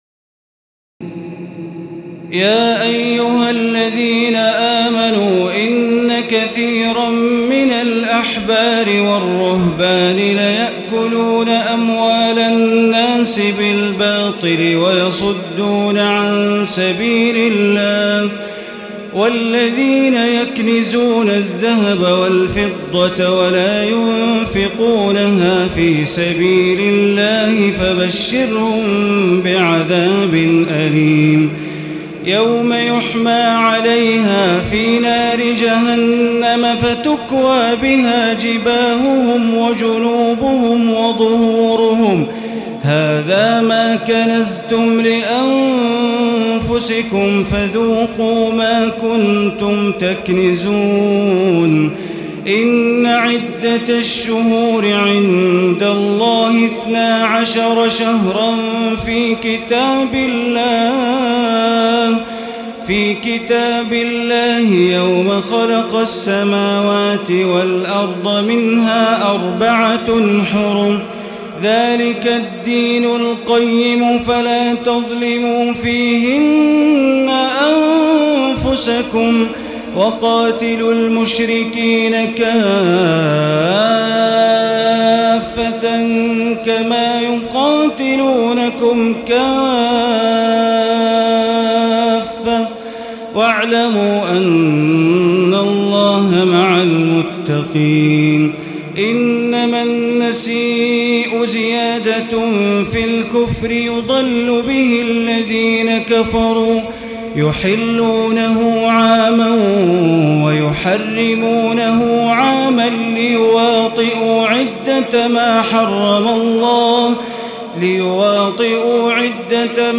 تراويح الليلة التاسعة رمضان 1436هـ من سورة التوبة (34-96) Taraweeh 9 st night Ramadan 1436H from Surah At-Tawba > تراويح الحرم المكي عام 1436 🕋 > التراويح - تلاوات الحرمين